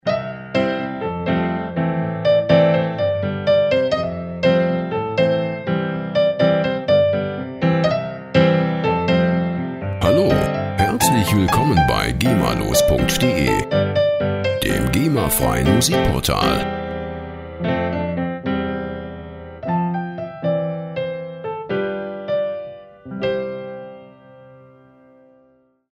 Der Klang der Musikinstrumente
Instrument: Klavier
Tempo: 123 bpm